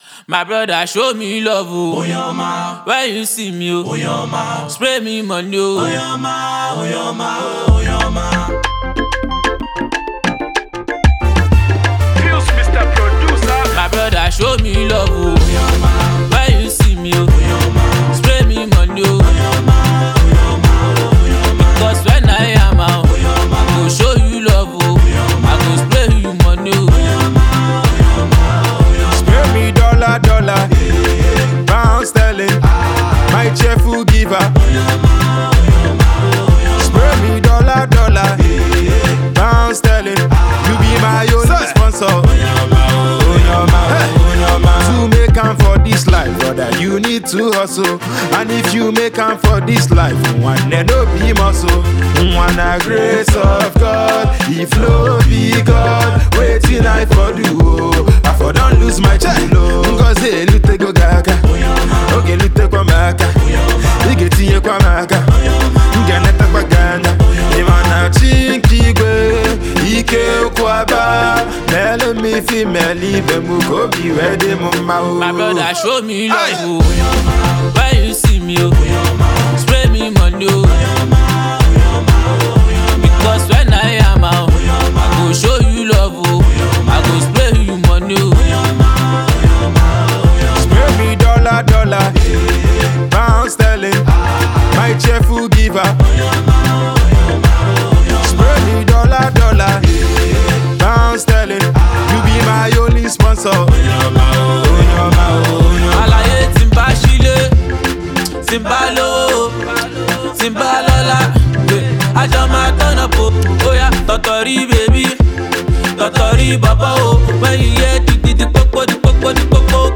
street jam
indigenous record